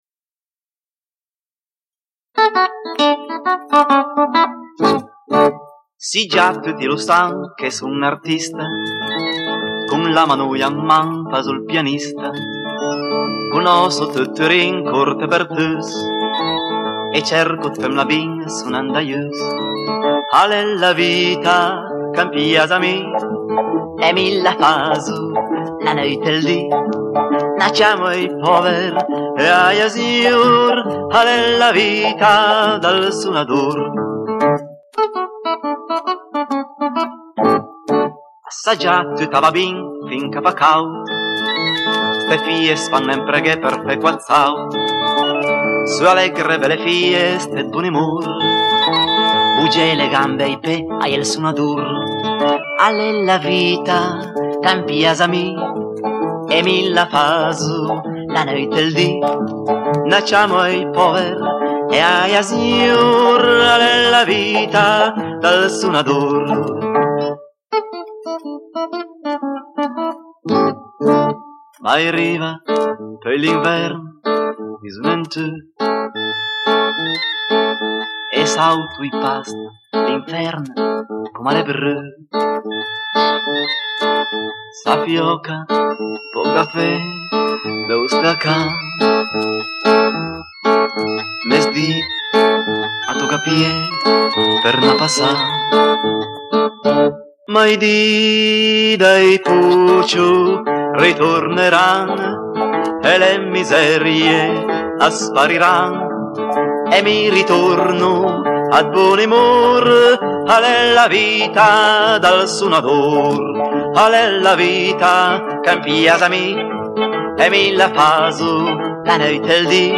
Canzone popolare